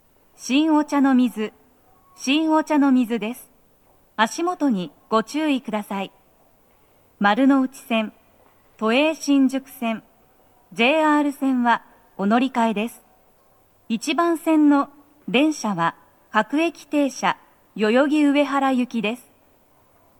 スピーカー種類 BOSE天井
足元注意喚起放送と、乗り換え放送が付帯するため、フルの難易度は高いです。
女声
到着放送1